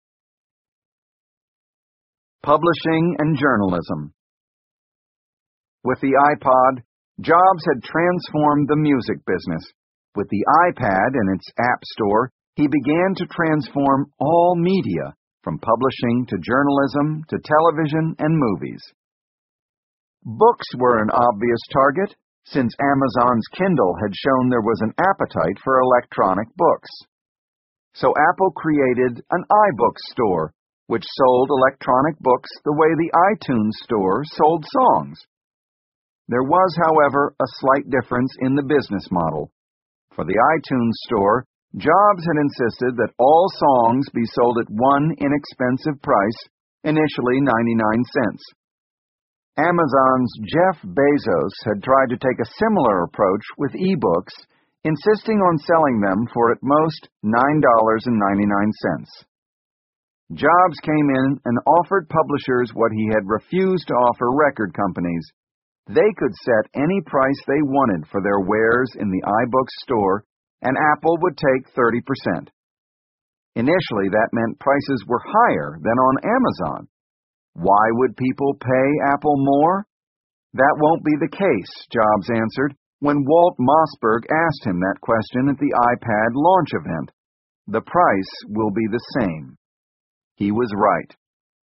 在线英语听力室乔布斯传 第699期:出版和新闻(1)的听力文件下载,《乔布斯传》双语有声读物栏目，通过英语音频MP3和中英双语字幕，来帮助英语学习者提高英语听说能力。
本栏目纯正的英语发音，以及完整的传记内容，详细描述了乔布斯的一生，是学习英语的必备材料。